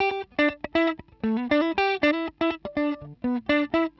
120_Guitar_funky_riff_E_8.wav